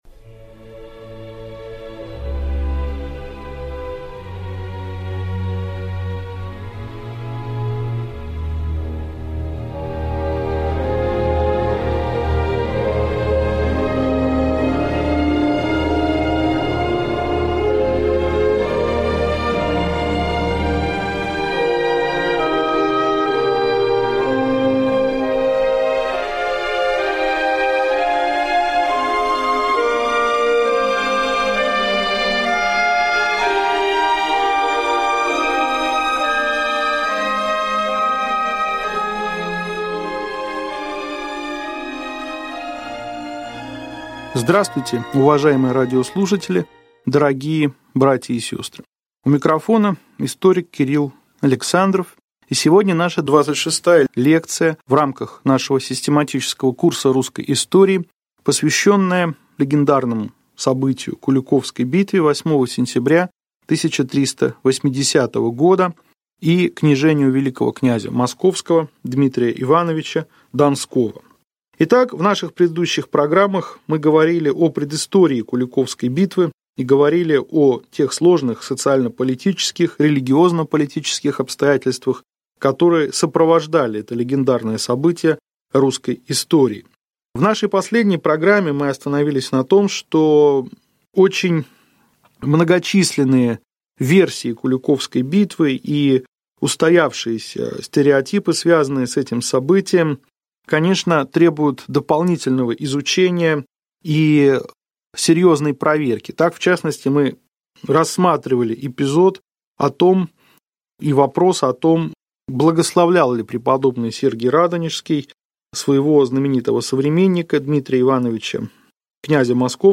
Аудиокнига Лекция 26. Куликовская битва | Библиотека аудиокниг